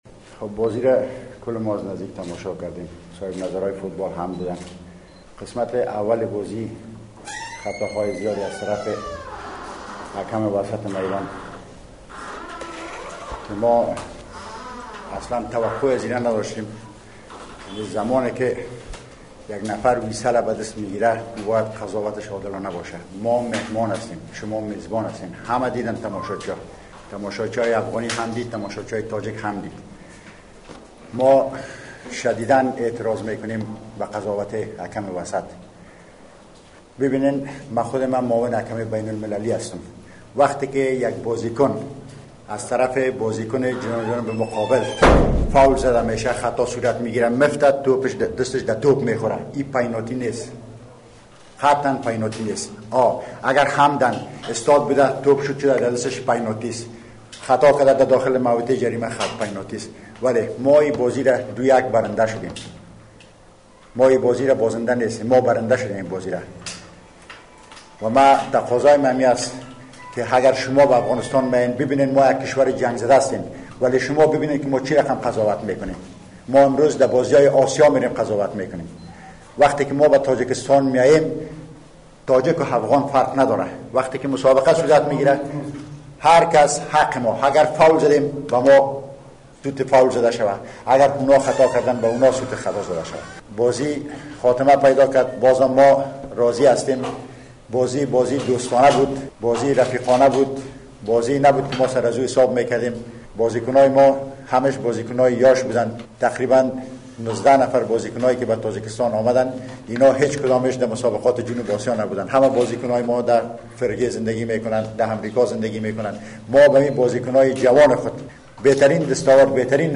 Нишасти хабарии сармураббиёни дастаҳои Афғонистон ва Тоҷикистон